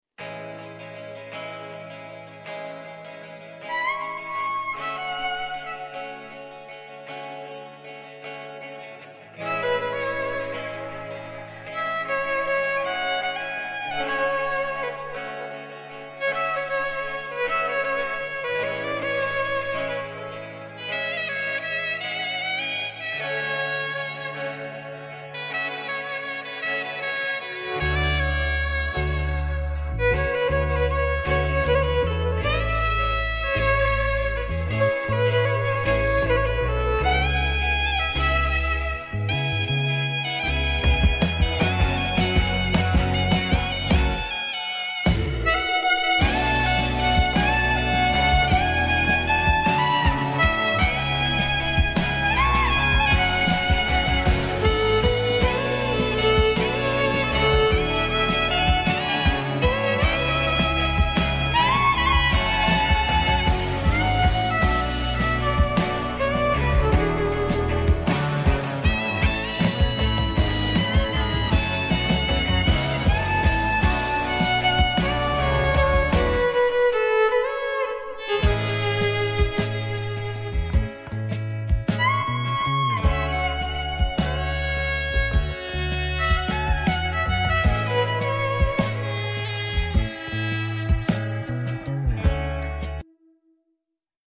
sax and Violin